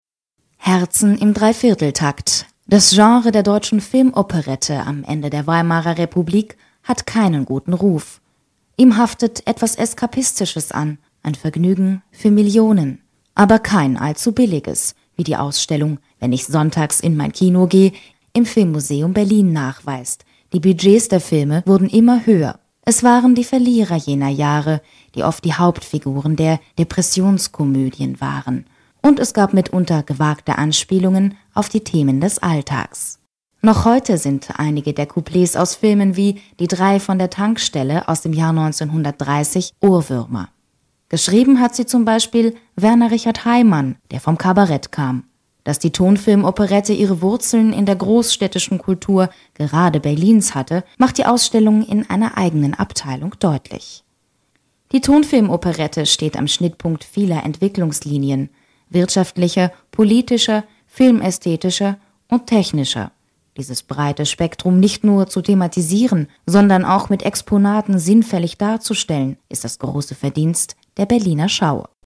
Sprechprobe: Industrie (Muttersprache):
german female voice over artist